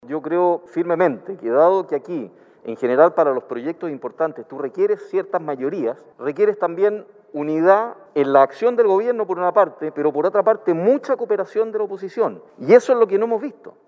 Desde Evópoli, el diputado Luciano Cruz Coke señaló que además de generar unidad al interior del Gobierno, debe existir un cambio y cooperación desde la oposición, para avanzar en los distintos proyectos.